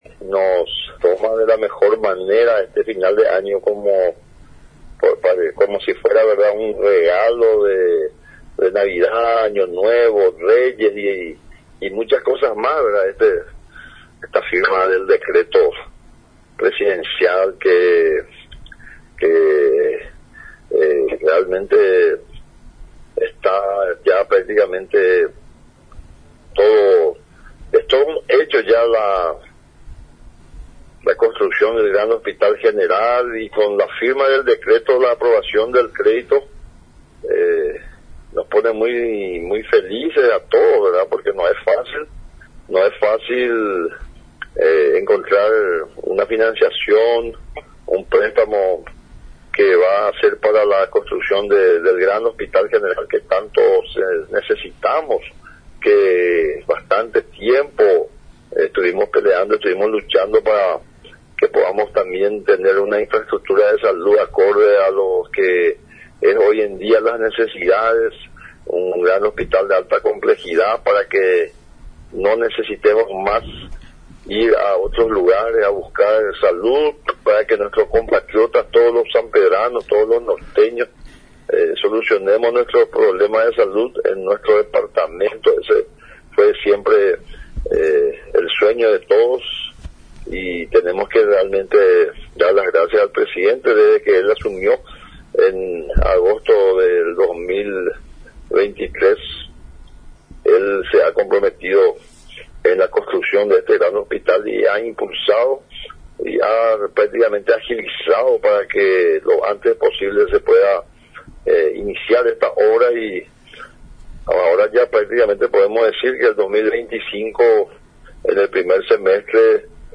EDITADO-4-FREDDY-DECCLESIIS-GOBERNADOR.mp3